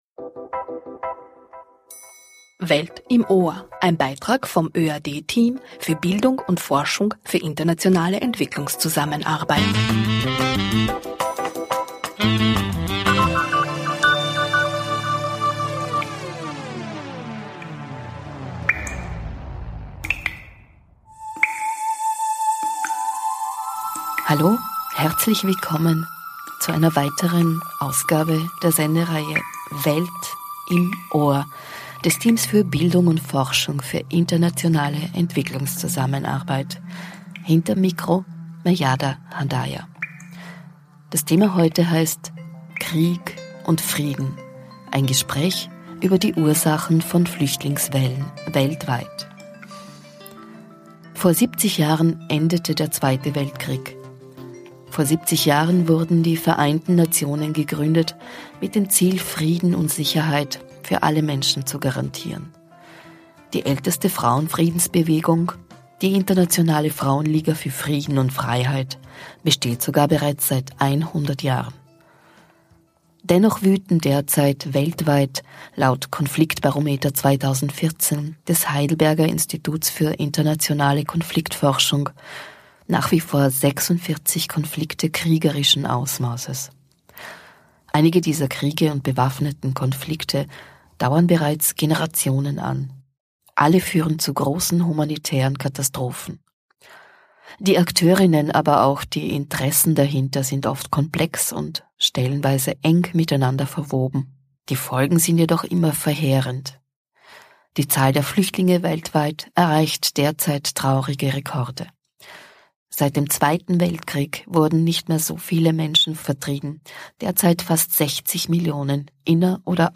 Krieg und Frieden: Ein Gespräch über die Ursachen von Flüchtlingswellen weltweit ~ Welt im Ohr Podcast